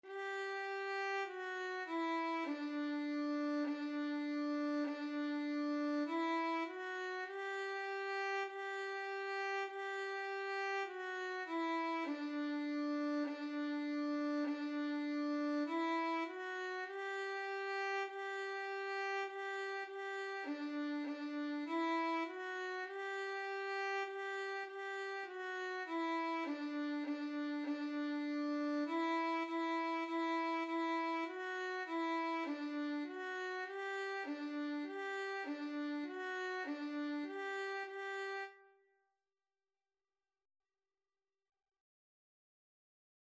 4/4 (View more 4/4 Music)
D5-G5
Violin  (View more Beginners Violin Music)
Classical (View more Classical Violin Music)